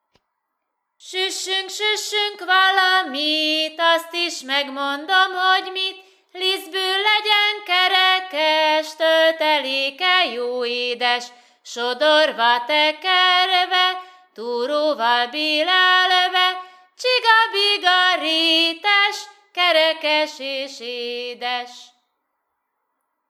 TípusI. Népi játékok / 07. Vonulások
TelepülésGács [Halič]